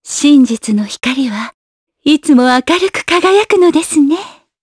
voices / heroes / jp
Yuria-Vox_Victory_jp.wav